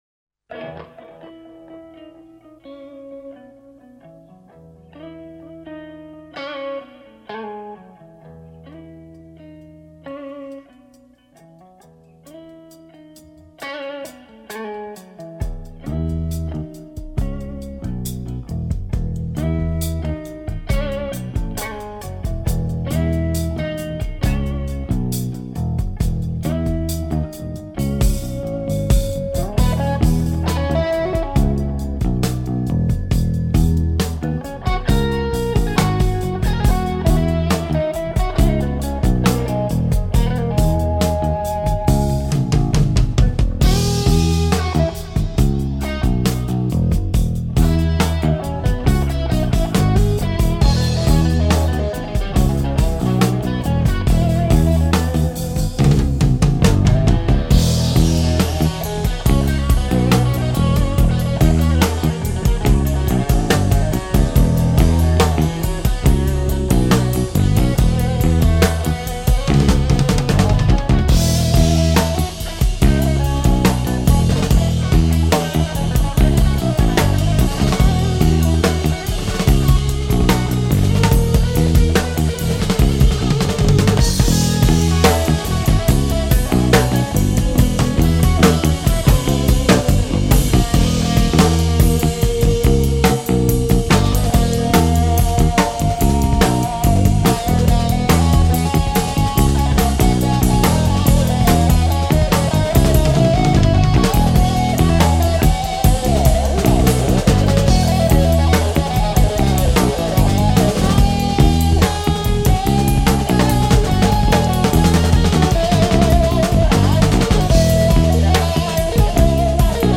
Итальянская группа прогрессивного рока